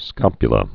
(skŏpyə-lə)